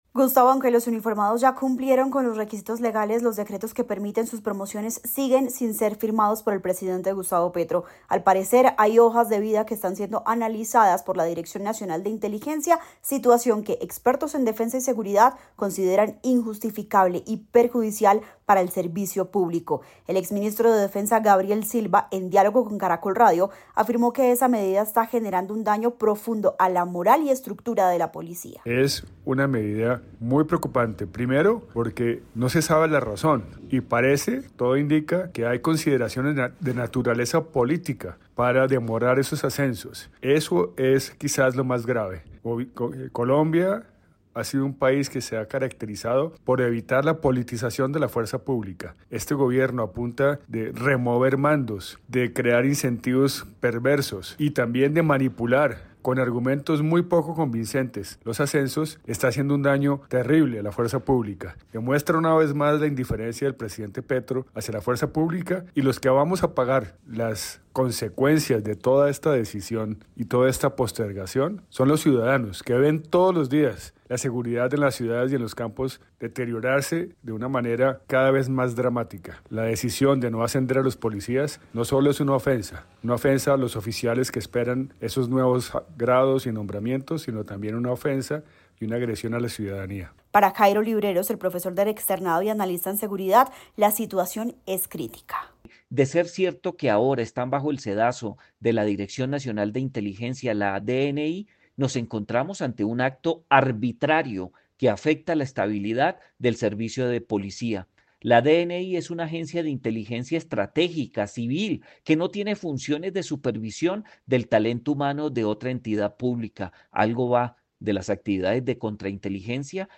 El Exministro de defensa Gabriel Silva en diálogo con Caracol Radio, advirtió que los efectos de esta decisión no se limitan a lo interno: